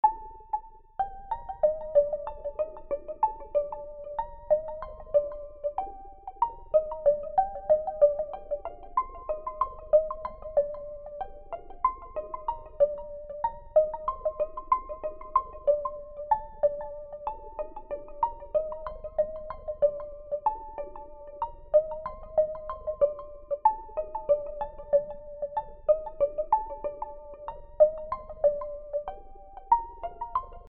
Solo (mélodique) de percu
Pour cette premiere partition solo la partie mutltiple à la croche sera jouée tel quel